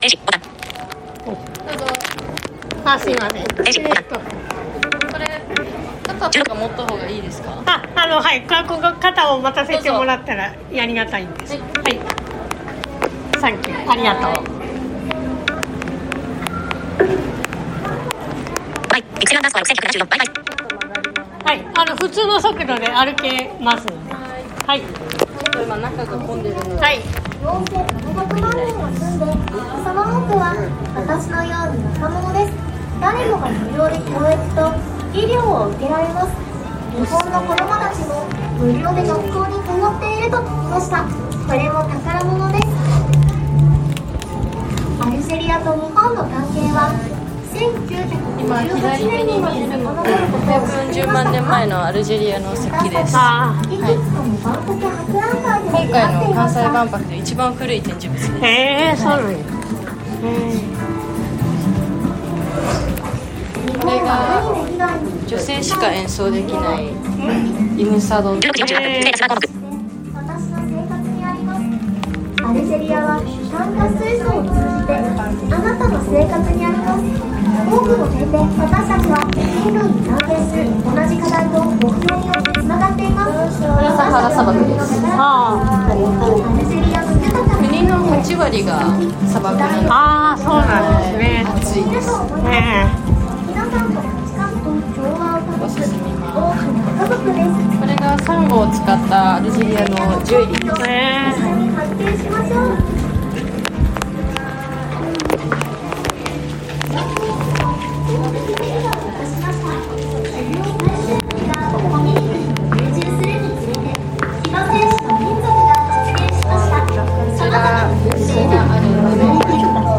映像や展示物を見て回るだけで、視覚障害者が触れるものは特になさそうでした。最後までノーカットで収録しております。